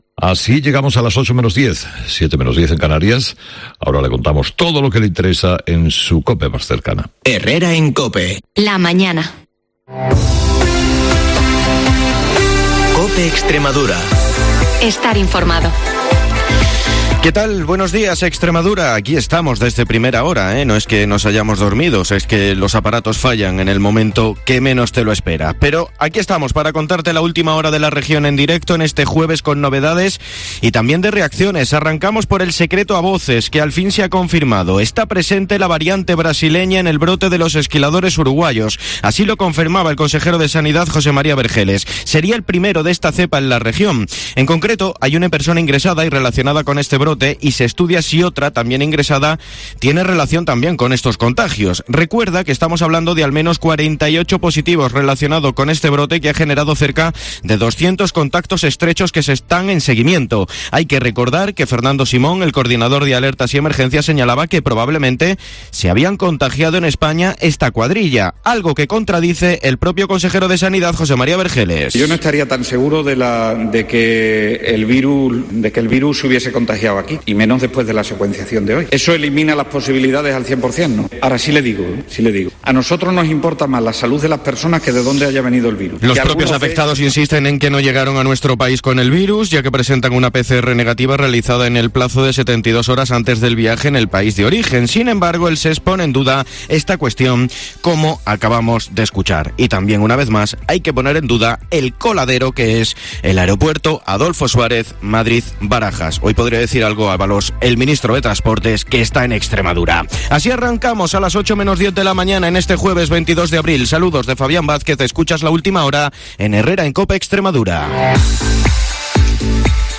De lunes a viernes a las 7:20 y 7:50 horas el informativo líder de la radio en Extremadura